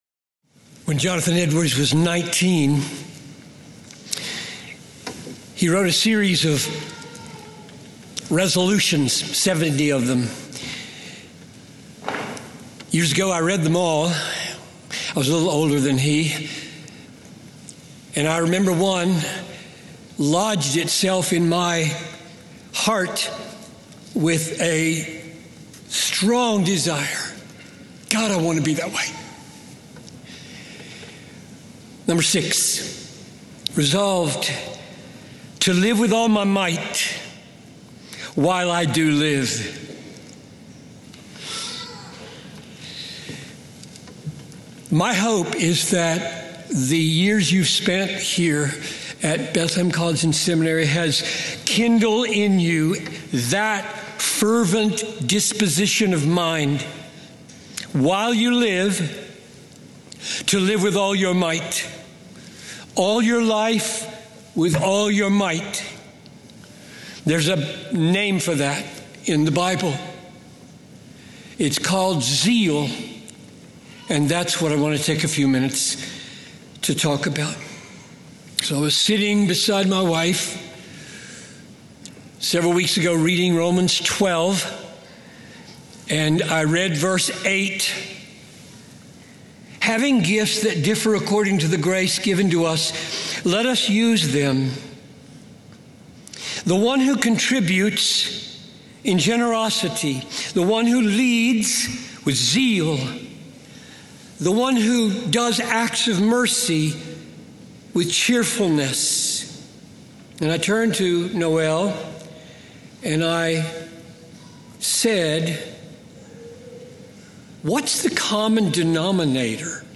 Commencement Address